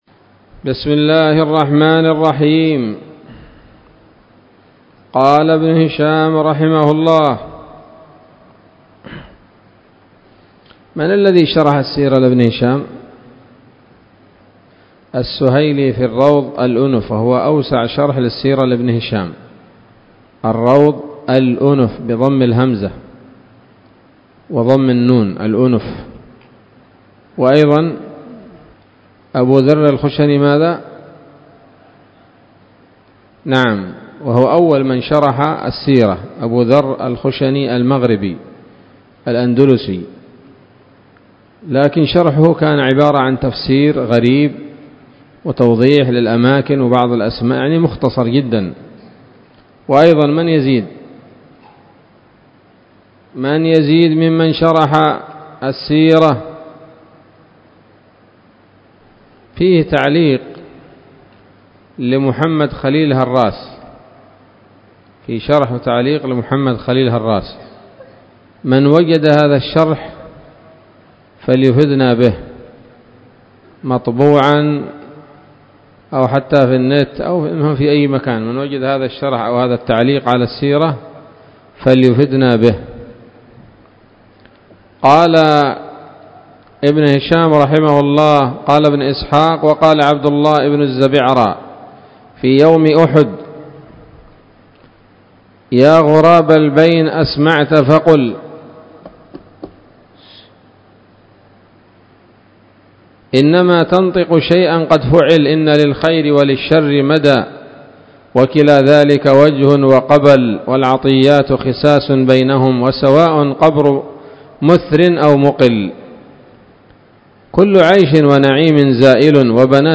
الدرس الثامن والسبعون بعد المائة من التعليق على كتاب السيرة النبوية لابن هشام